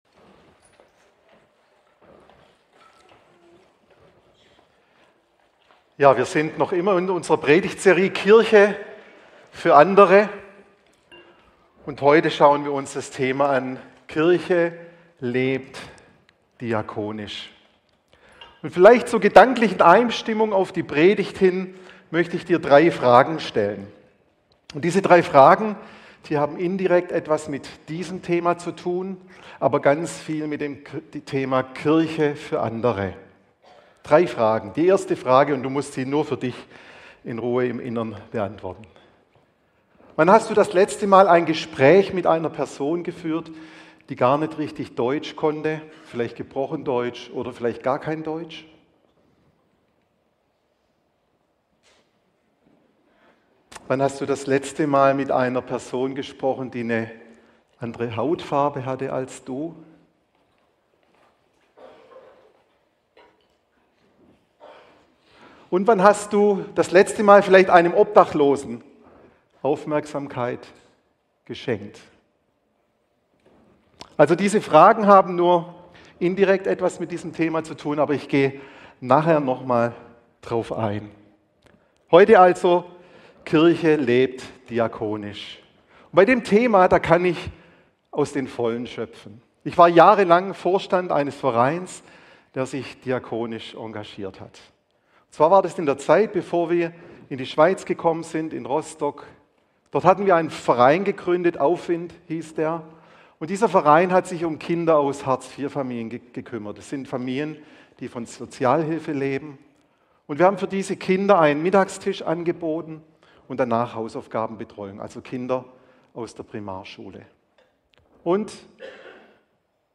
Warum das richtige Know-How allein nicht reicht und was Mutter Teresa mit den ersten Christen im Römischen Reich gemeinsam hatte - darum wird es in der Predigt gehen.